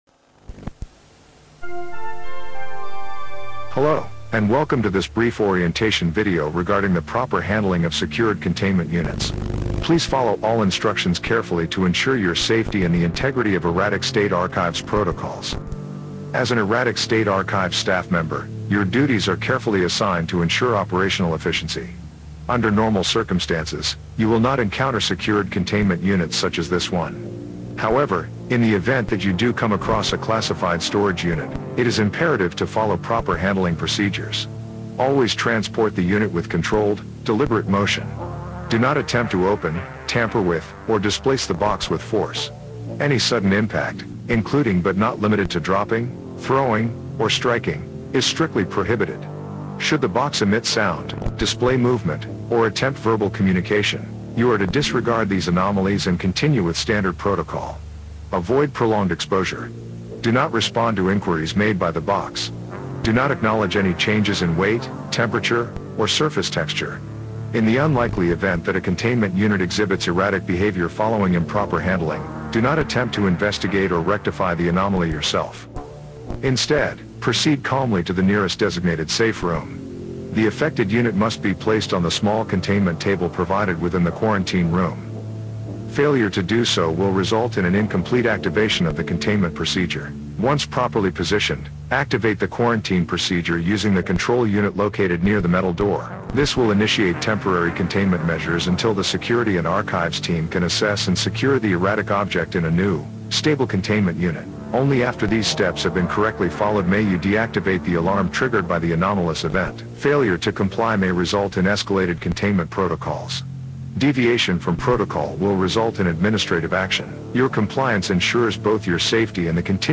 Proper containment procedures save lives. This instructional tape outlines the fundamentals of handling containment units.